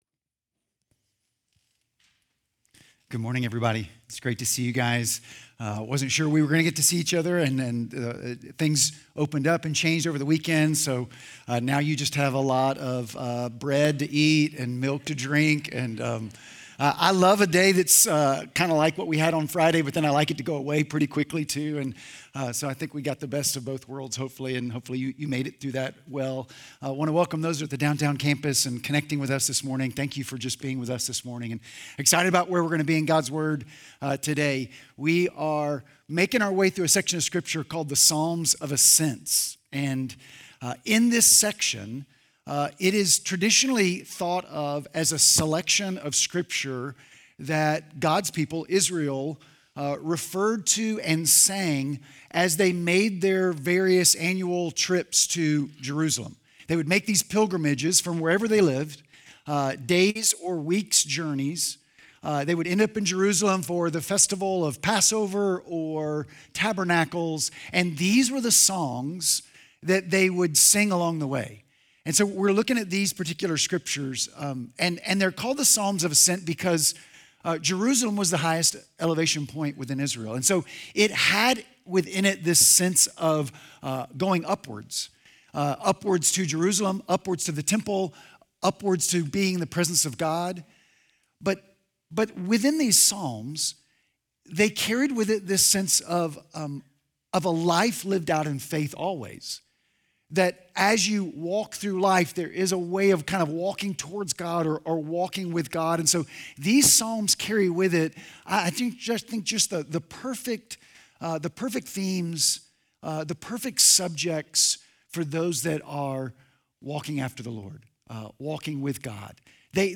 Sermon Notes Sermon Audio…